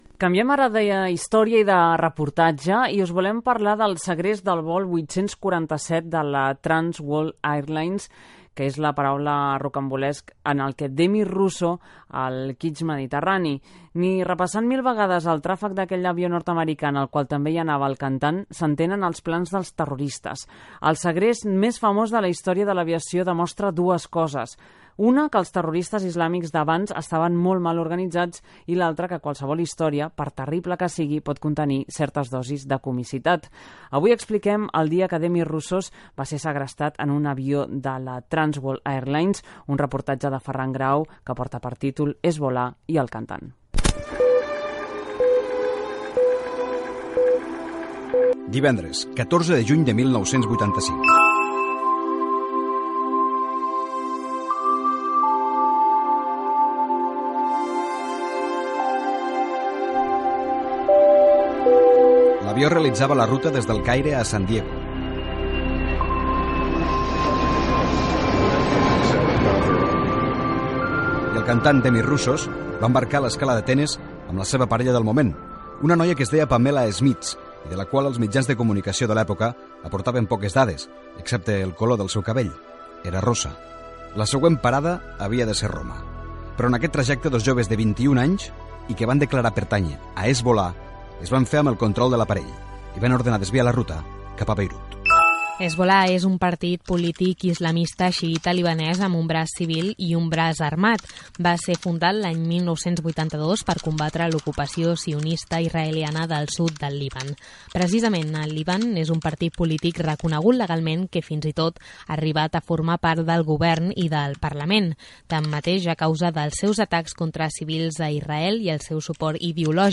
Reportatge
Gènere radiofònic Info-entreteniment